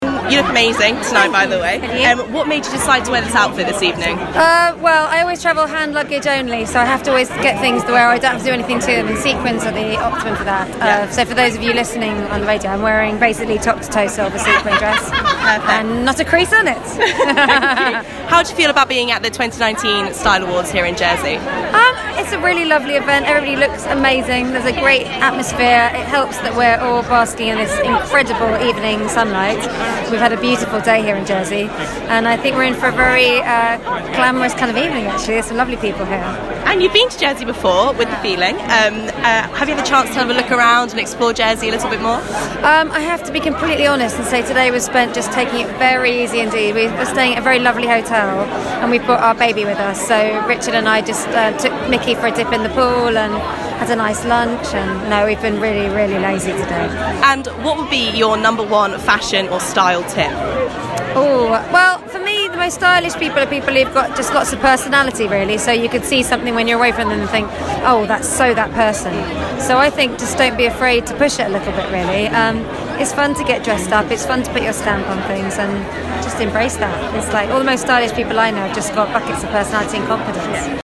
Interviewing Sophie Ellis Bextor at the Jersey Style Awards
I interviewed Sophie Ellis Bextor at the Jersey Style Awards and she was LOVELY. She was so chatty, kind and I absolutely loved what she was wearing.